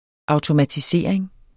Udtale [ ɑwtomatiˈseˀɐ̯eŋ ]